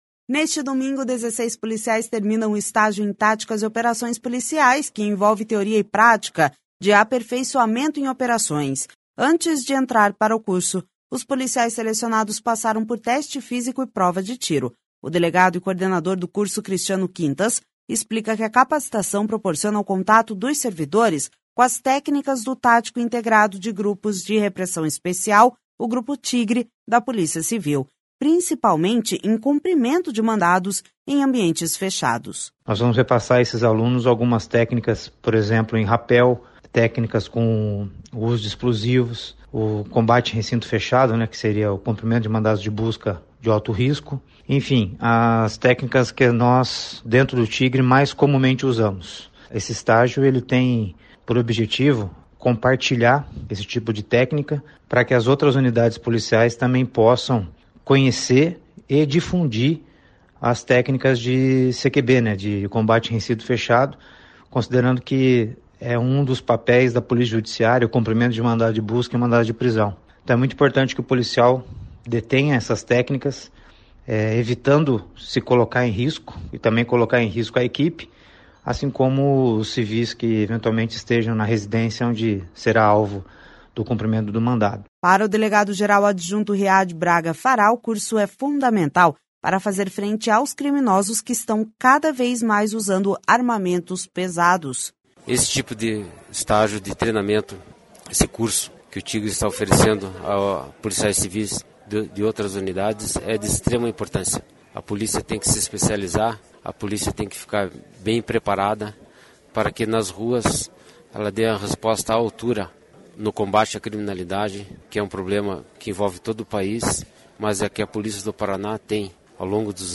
Para o delegado-geral adjunto, Riad Braga Farhat, o curso é fundamental para fazer frente aos criminosos, que estão cada vez mais usando armamentos pesados.// SONORA RIAD BRAGA FARHAT//